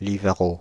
French pronunciation of « Livarot »